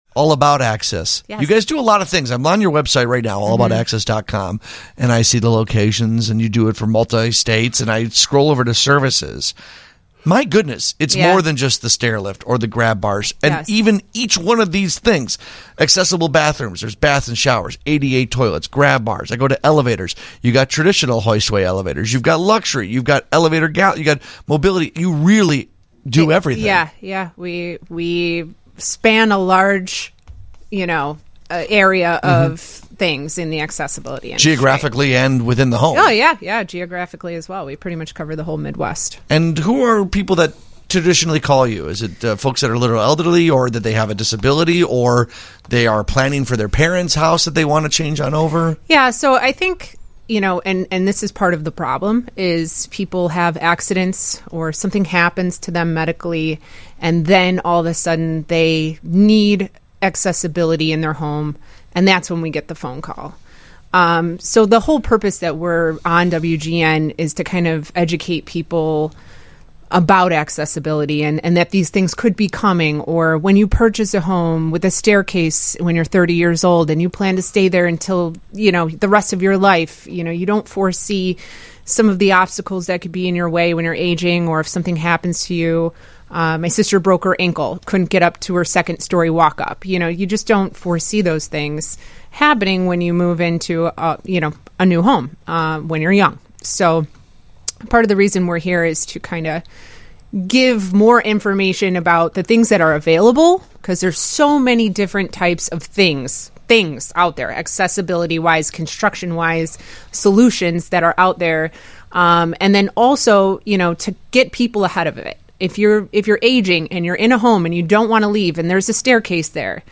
Featured on WGN Radio’s Home Sweet Home Chicago on 09/13/2025